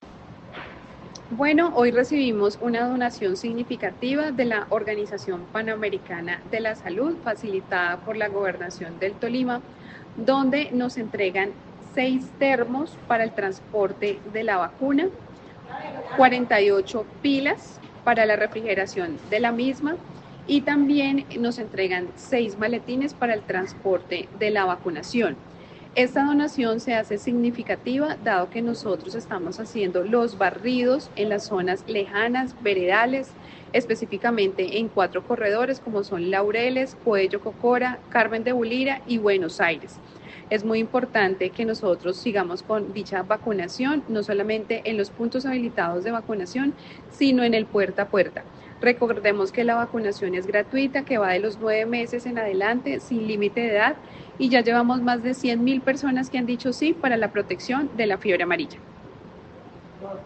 Escuche las declaraciones de la secretaria de Salud Municipal, Liliana Ospina: